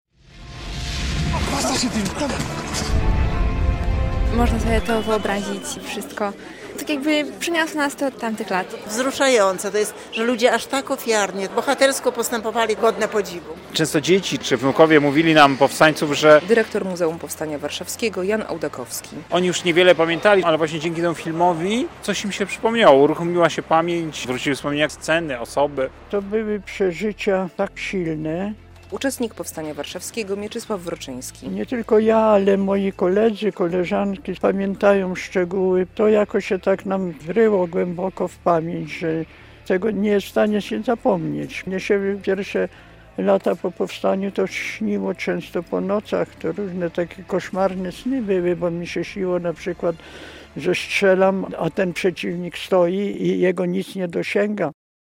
Dyskusja w kinie Helios była połączona z prezentacją filmu "Powstanie Warszawskie".[/b]